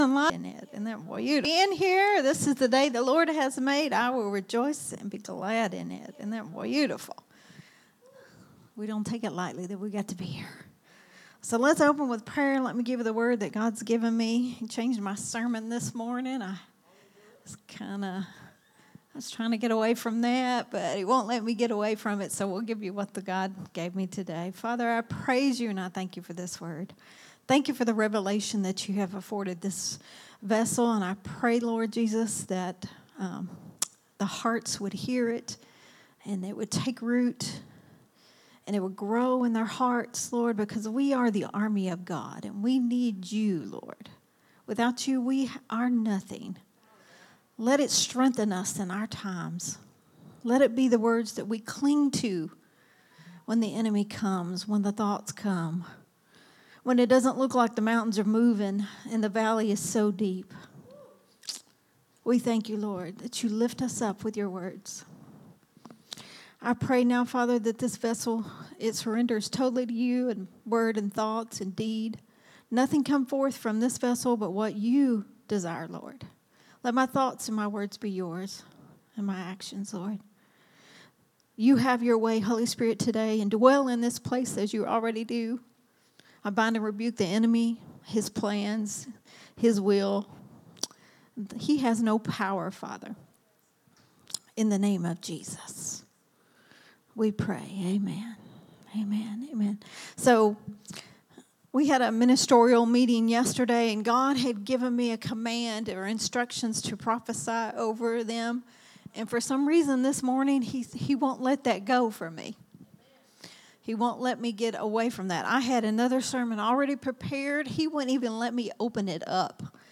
a Sunday Morning Risen Life teaching
recorded at Growth Temple Ministries on Sunday, September 21, 2025.